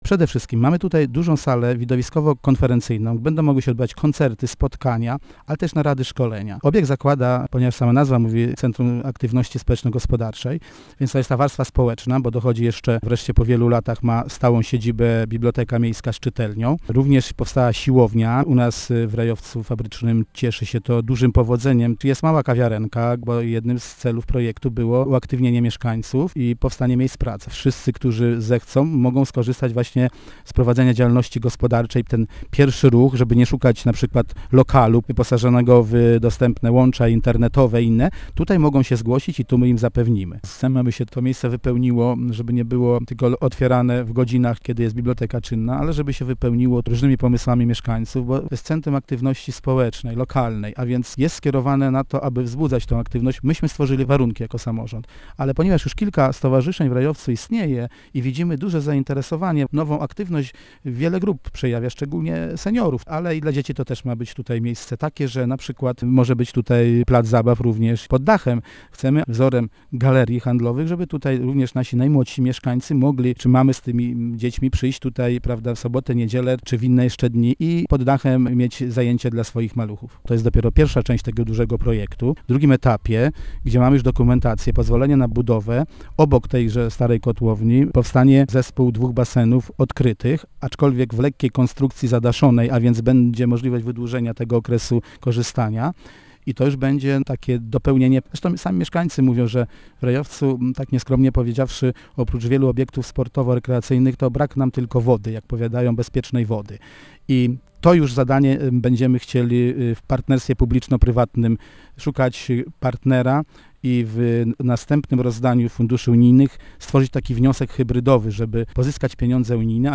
Tym bardziej, że to nie ostatni etap zagospodarowywania tej części miasta - mówi Informacyjnej Agencji Samorządowej burmistrz Rejowca Fabrycznego Stanisław Bodys: